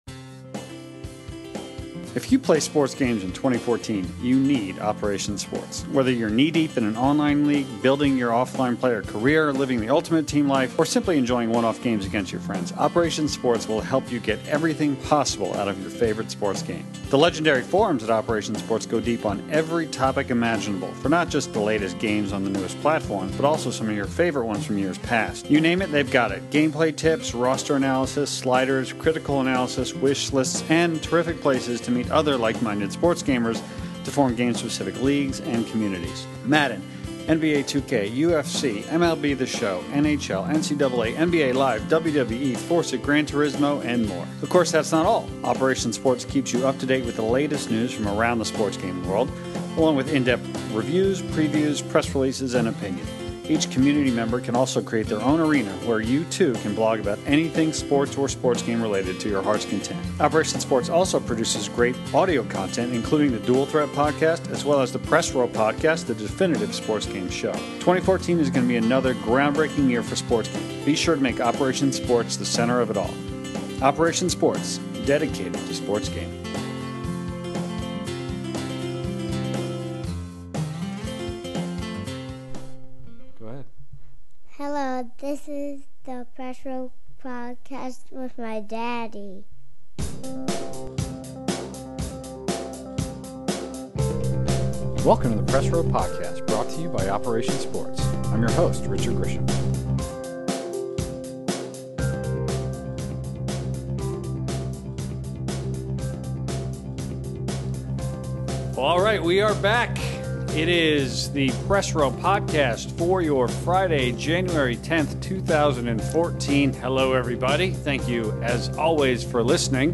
It’s the 67th episode of the Press Row Podcast, and our regular panel is back to dive into a packed agenda. There is a discussion about the ongoing struggles and concerns of NBA 2K14 on next-gen consoles, the potentially concerning news – and lack thereof – emanating from Sony about MLB 14 The Show on the PS4, and a review of sorts of Powerstar Golf on the Xbox One.